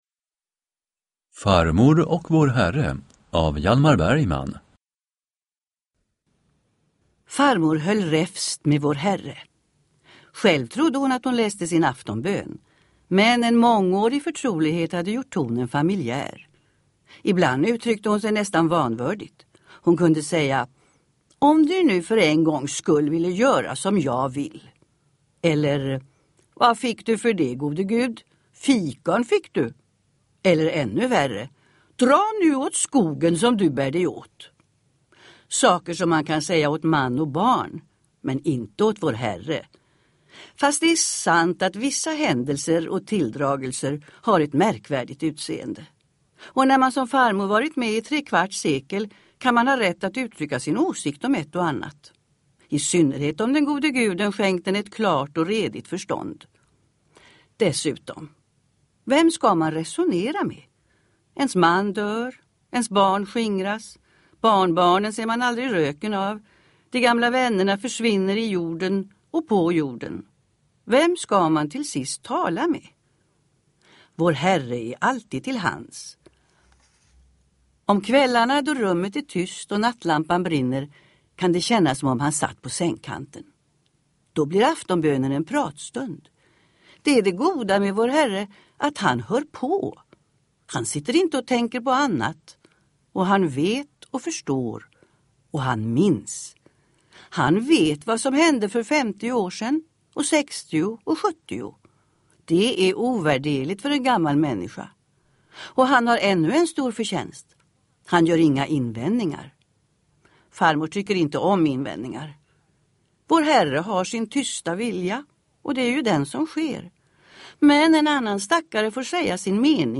Uppläsare: Meta Velander
Ljudbok